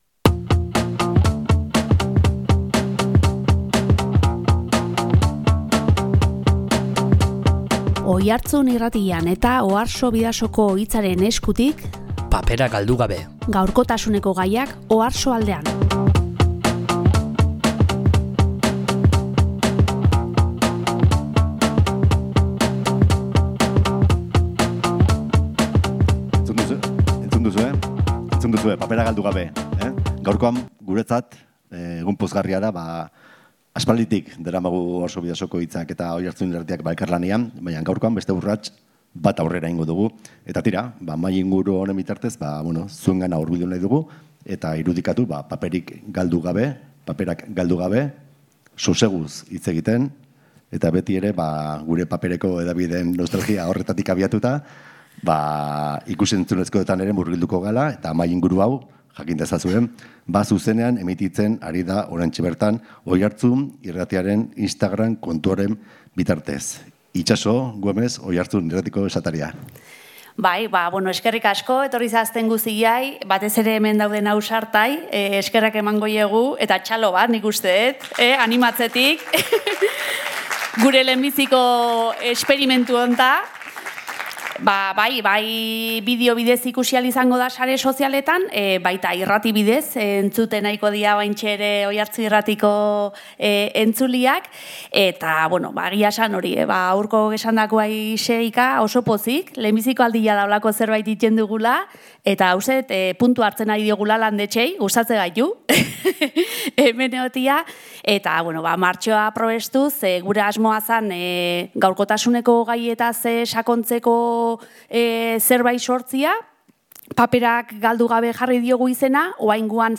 Oiartzun Irratiaren eta OARSO BIDASOKO HITZAren elkarlanetik abiatutako Paperak Galdu Gabe mahai-inguru ziklotik lehena egin zen joan den martxoaren 7an Oiartzungo Landetxen.